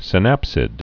(sĭ-năpsĭd)